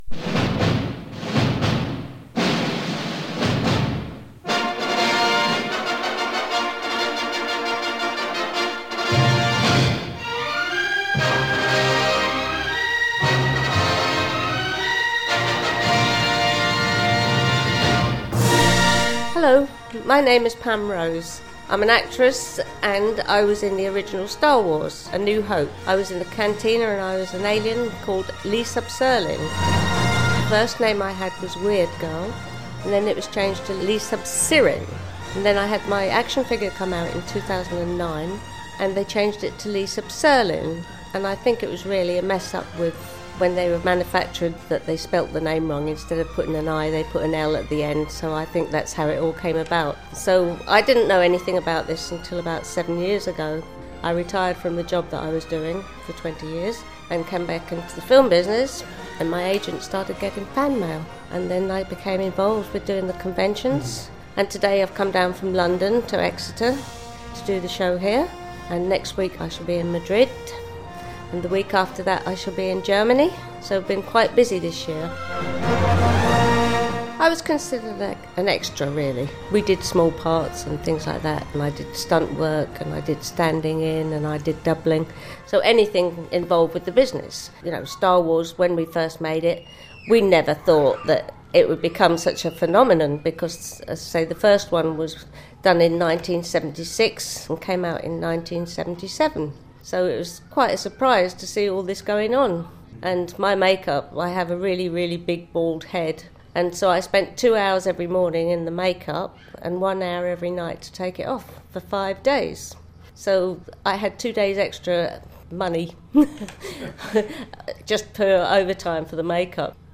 Star Wars Interview